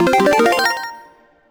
2x_gatcha_double_01.wav